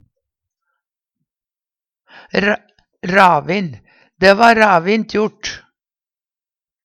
DIALEKTORD PÅ NORMERT NORSK ravin ravin, raskt Eksempel på bruk Dæ va ravint jort.